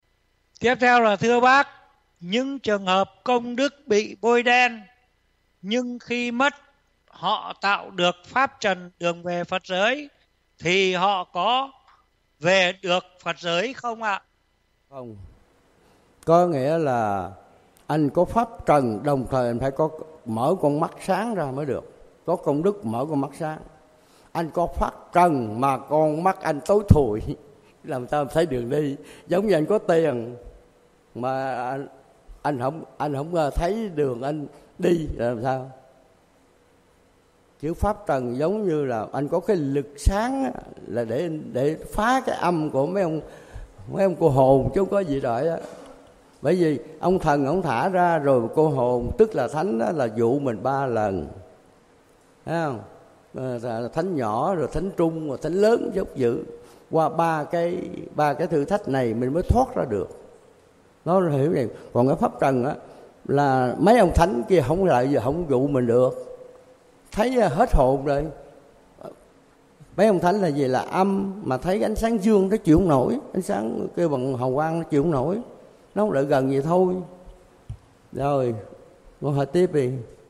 Trò hỏi:
Thầy trả lời: